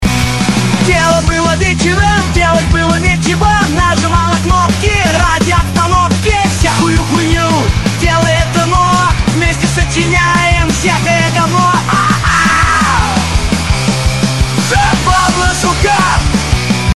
В моем примере выше он пару раз взвизгнул то добротно, я так не умею. Да оно там вообще оригинально придумыает и вполне реалистично при том, эмоциональная часть и всякие акценты проработоны иногда прям на удивление )) Иногда конечно чушь полную выдает, случается )